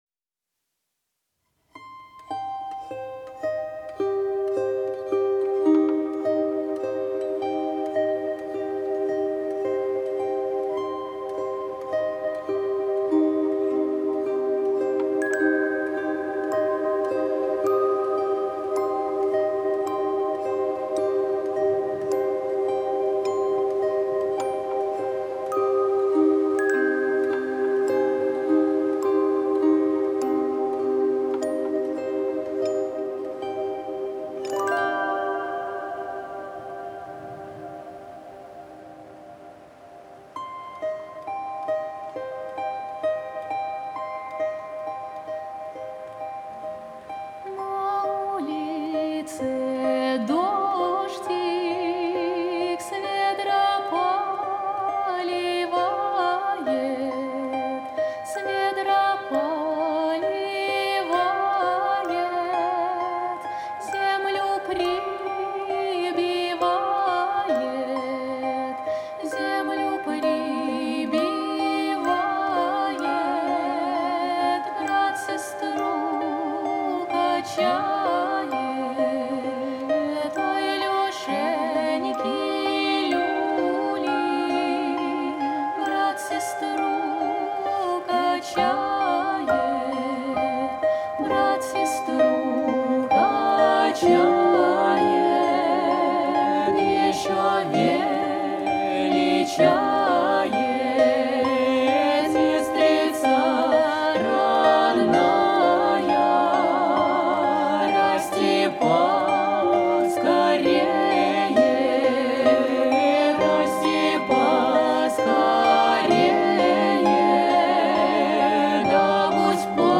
Жанр: Народная музыка